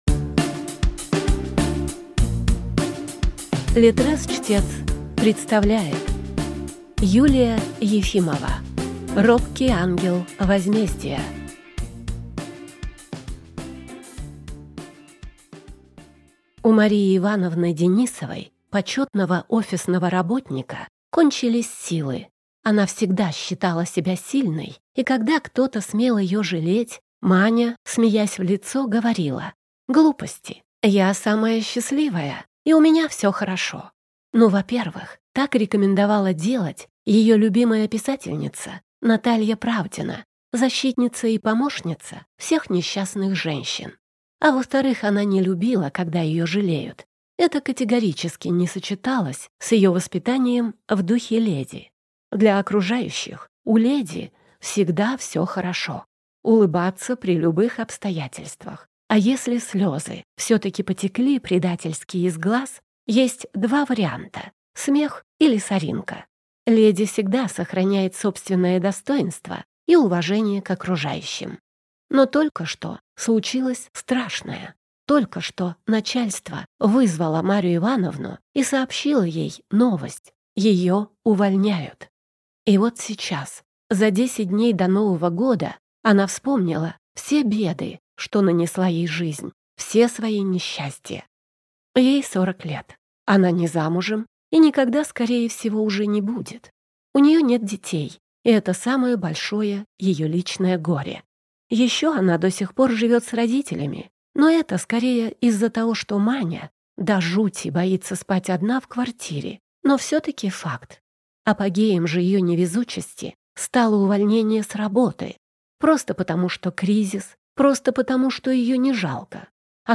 Аудиокнига Робкий ангел возмездия | Библиотека аудиокниг
Прослушать и бесплатно скачать фрагмент аудиокниги